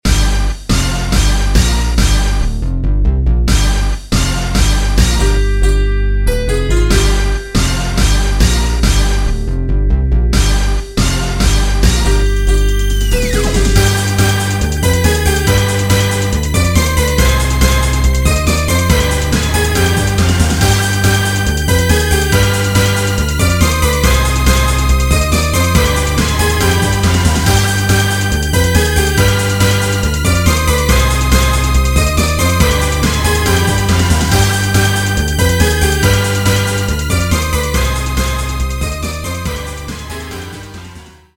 The mixage is so so, not bad not good.
Compo 7 uses this weird orchestral hits that don't appeal to me a lot, the harmony is also taken from something else, but that's okay.
Yeah, the timpani hits are too repetitive for me.